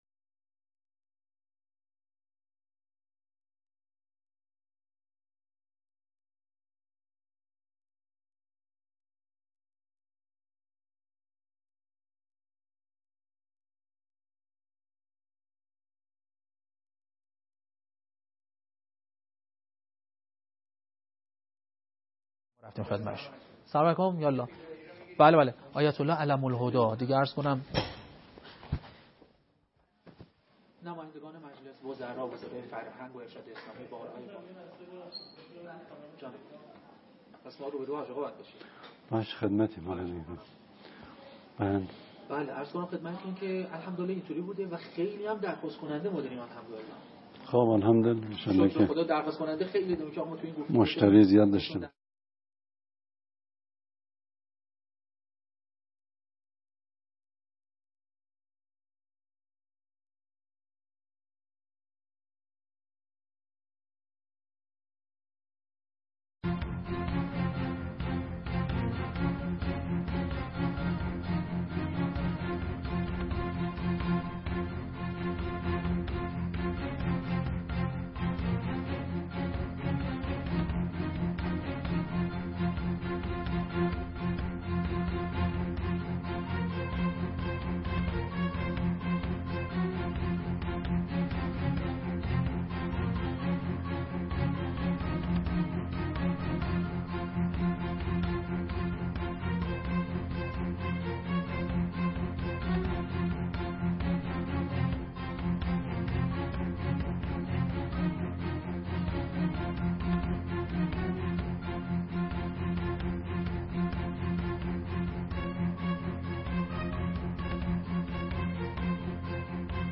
حجت الاسلام علی محمدی رئیس سازمان اوقاف و امور خیریه با حضور در برنامه گفت وگوی ویژه شبکه قرآن به سوالات پاسخ داد.